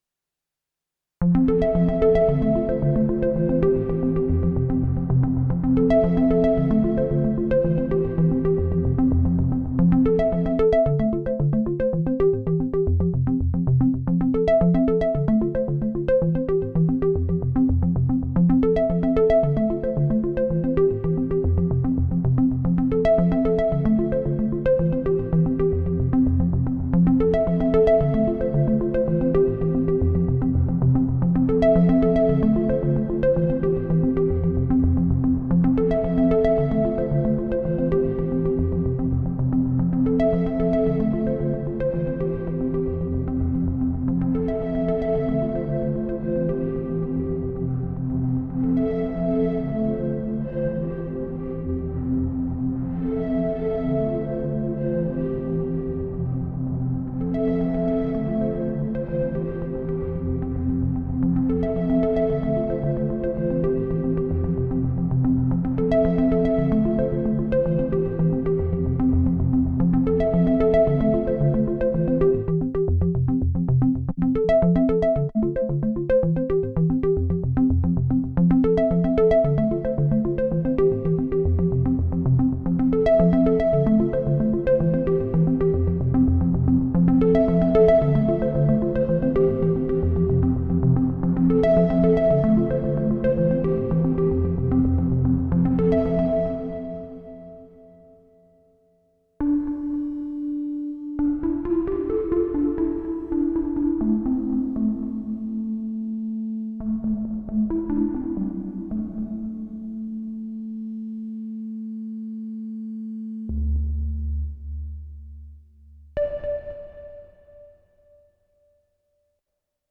Выкладываю по просьбе одного нашего камрада примеры того, как звучат встроенные ревера на борту Ob-6. Там есть hall, room, plate, spring. Наиграл и записал примеры всех алгоритмов (с разными настройками подмешивания, хвостов и тона) на двух разных звуках Ob-6.
В файлах есть паузы, иногда несколько секунд, не выключайте запись раньше времени.